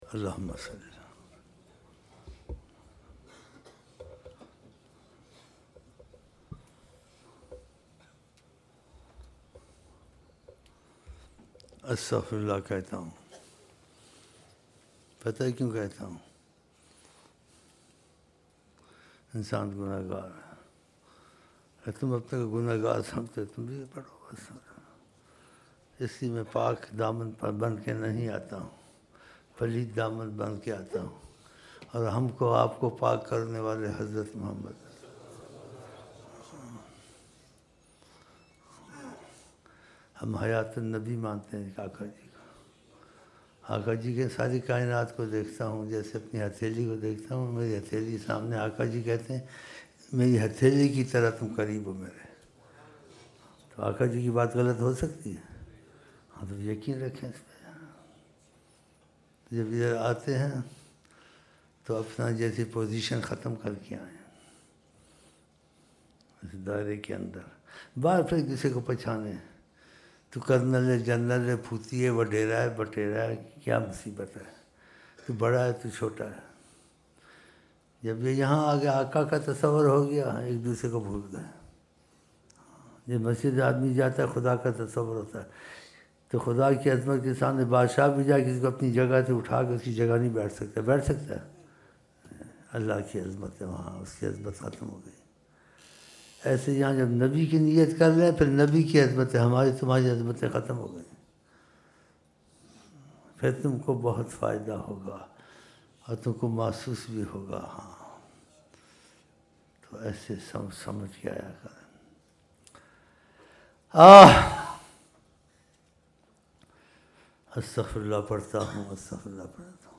Dua: 02 January 2007 Isha Mahfil